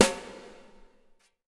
BW BRUSH01-R.wav